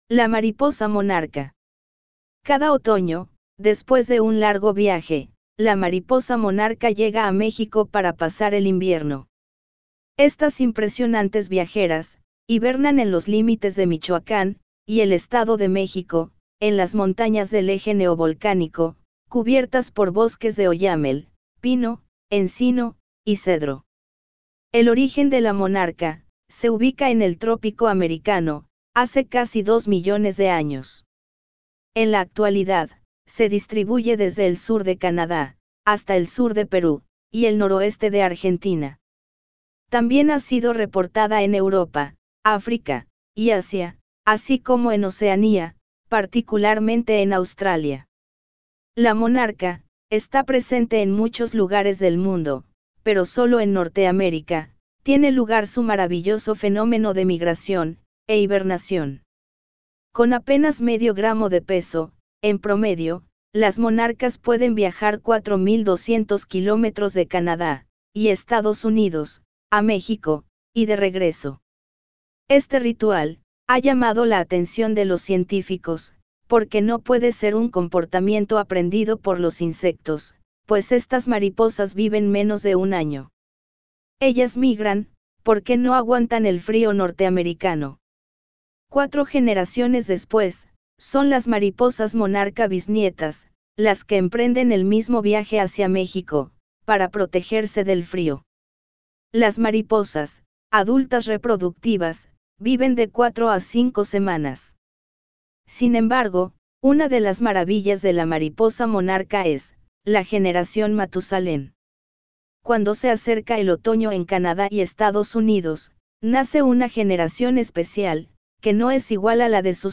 B1-La-mariposa-monarca-balabolka.wav